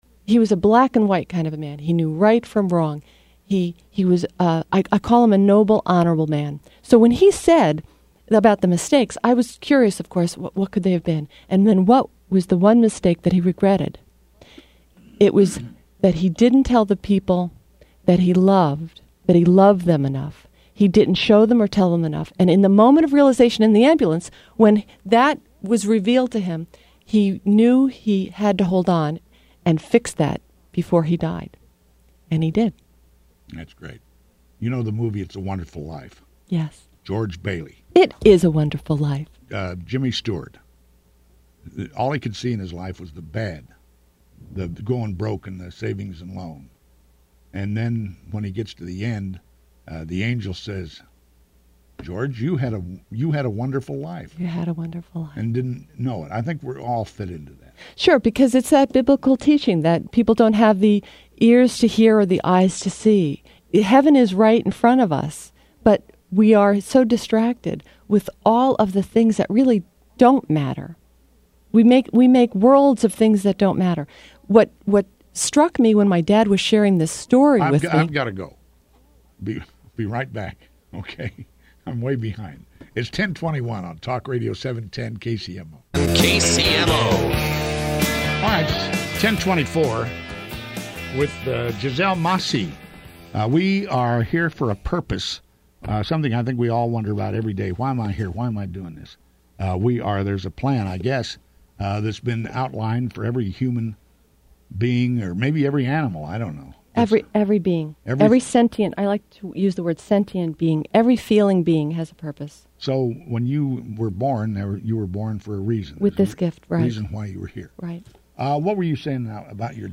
Media, Radio